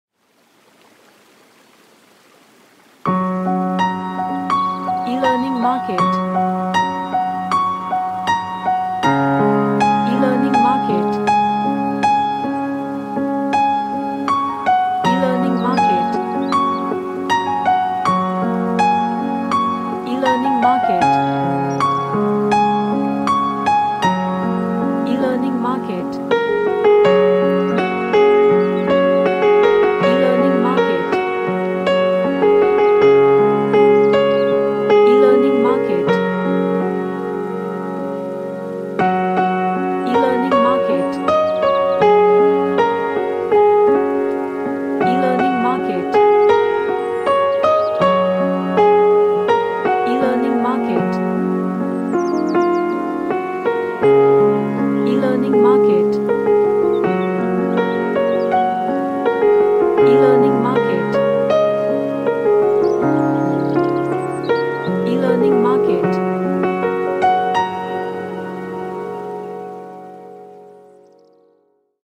A cinematic happy sounding piano track
Happy / Cheerful